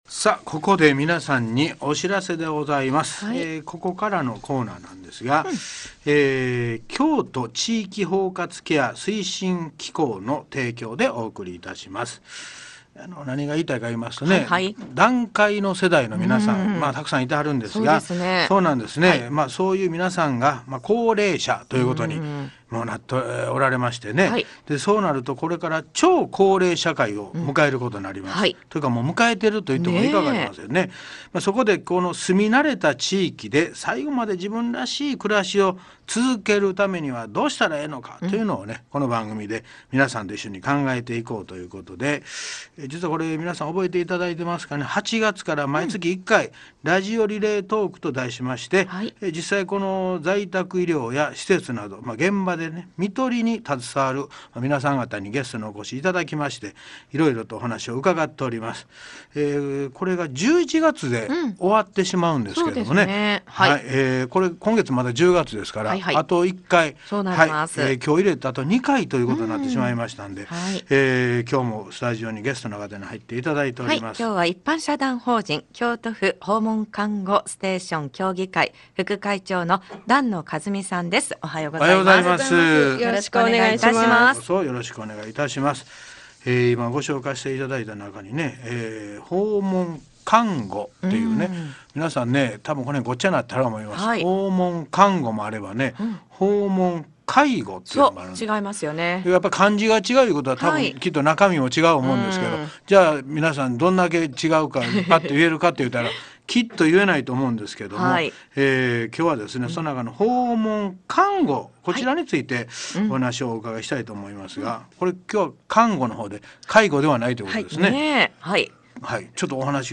在宅や施設で看取りに取り組む医師、訪看、施設職員、介護支援専門員等をゲストに迎え、心に残る看取りの実践事例などをお話いただきました。 (平成29年度 KBS京都「笑福亭晃瓶のほっかほかラジオ」内で実施)